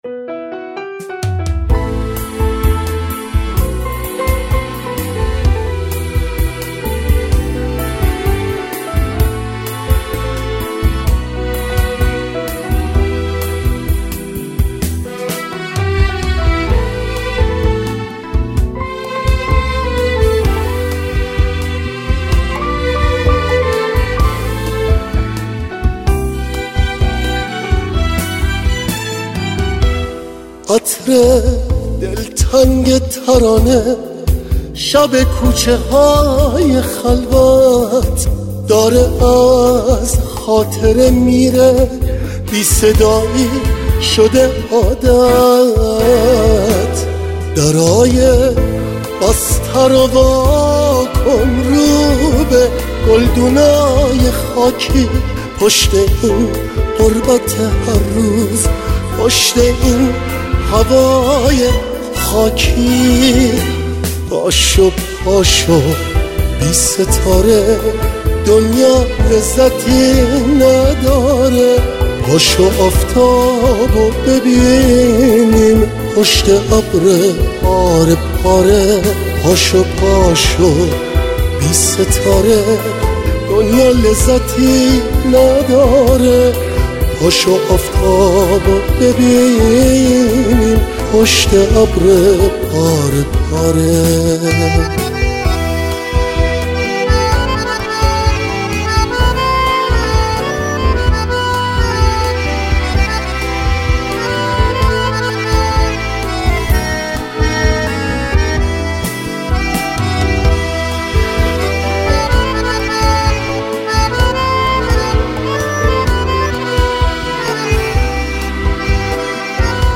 хонандаи эронӣ